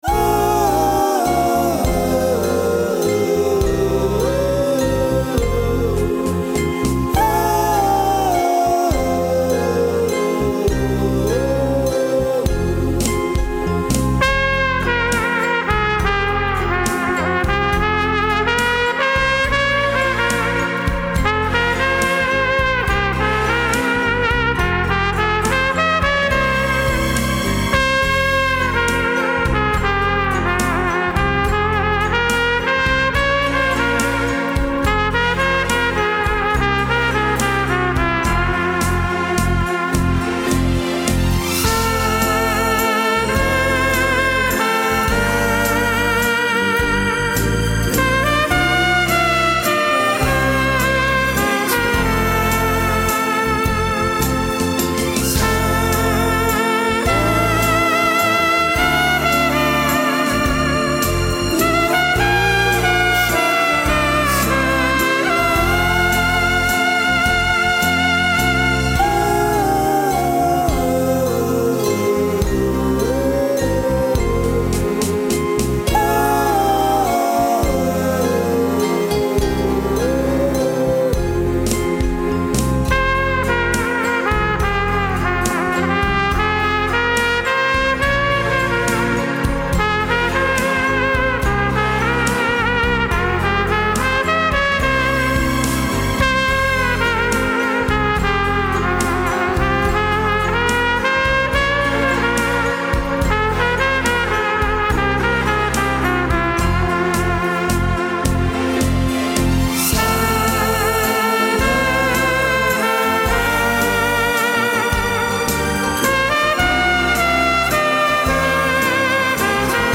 この曲は、そのうち演奏してみようと思ってカラオケだけ準備していたものです。
拙い演奏ですが、ここに掲載させていただきます。